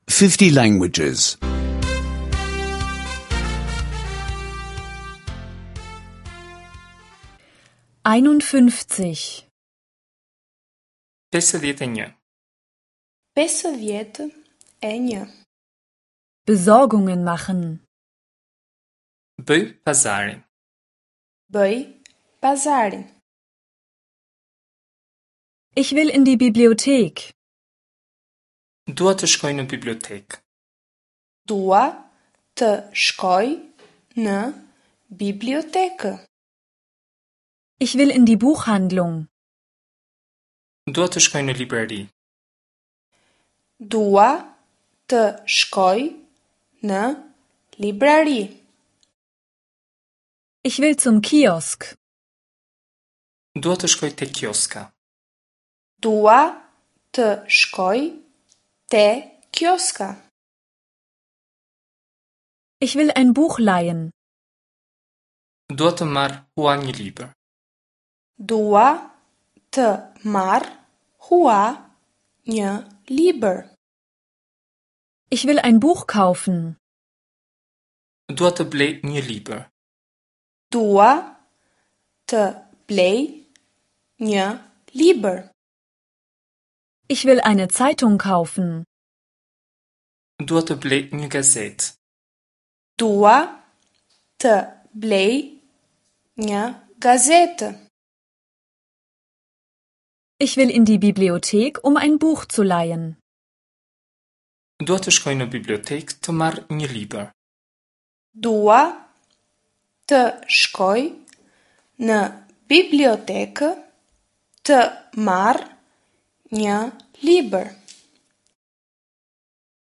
Albanischen Audio-Lektionen, verfügbar zum kostenlosen Download per Direktlink.